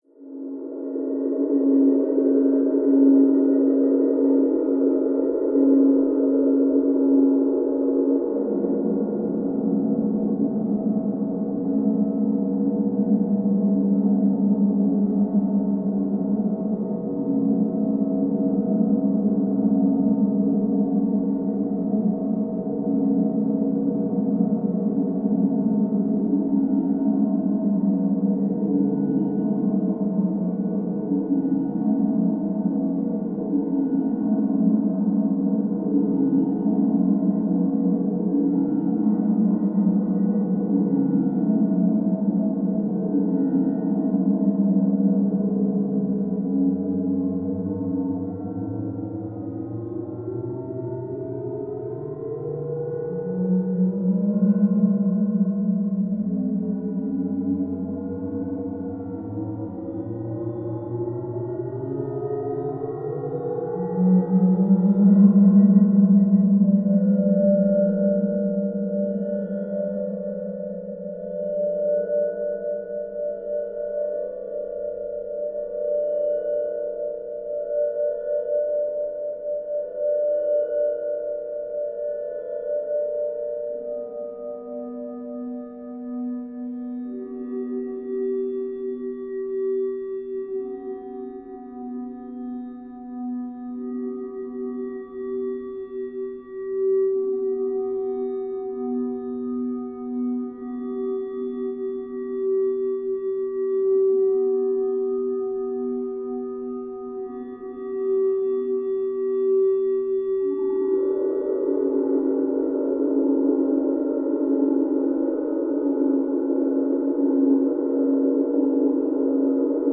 Hollow Wind with Portamento At Mid Wind, Hollow